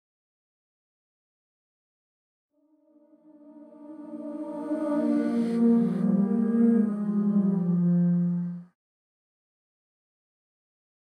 Reverse Reverb ist ein spezieller Effekt, bei dem der Hall rückwärts abgespielt wird, sodass der Klang langsam anschwillt. Dies erzeugt eine mystische, unheimliche Atmosphäre und wird oft für kreative Soundeffekte oder in Ambient- und Psychedelic-Musik verwendet.
Im folgenden Soundbeispiel hören Sie einen Gesang mit einem Rückwärtshall:
Vocals-reverse-hall.mp3